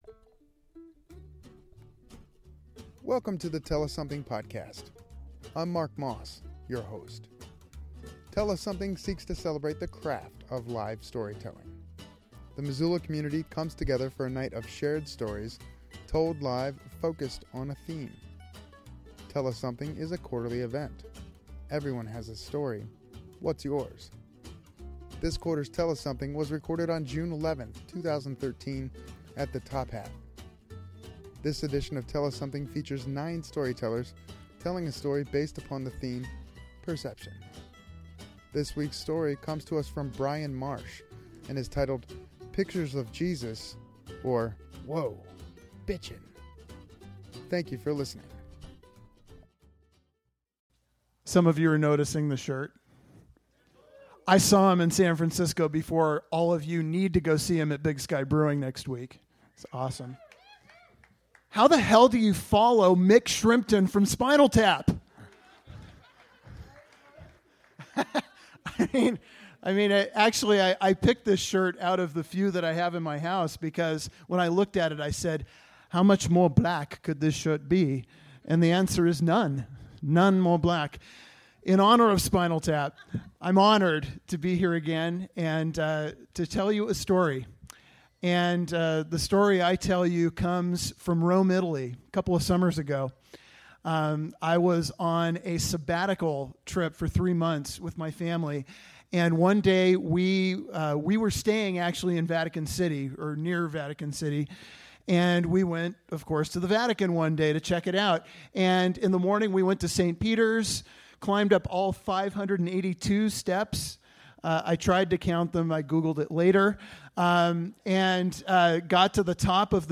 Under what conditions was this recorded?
This quarter’s Tell us Something was recorded on June 11th, 2013 at the Top Hat Lounge in Missoula, MT. The theme was “Perception”.